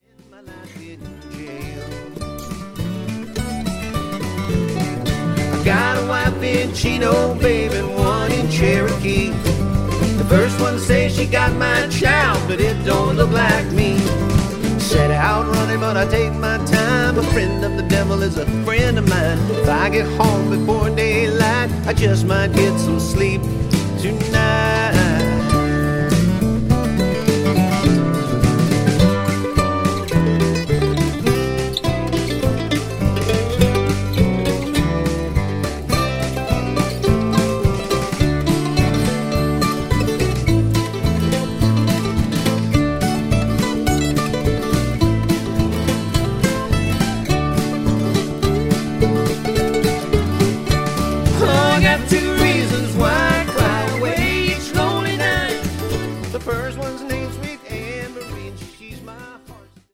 harmony-rich acoustic style